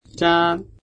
韓国人講師の音声を繰り返して聞きながら発音を覚えましょう。
韓国語では「짠」と記載され、「チ」の前に小さな「ッ」をつけて「ッチャン」と発音するのがコツです。
発音と読み方
[チャン]
1．日本語の小さな「ッ」が入るイメージ、2．息が極力出ないよう喉を締め付け、絞るイメージ。舌を奥に引き、口からではなく喉から発声する、3．中国語っぽくトーンを高く